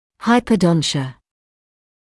[ˌhaɪpəˈdɔntɪə][ˌхайпэˈдонтиэ]гипердонтия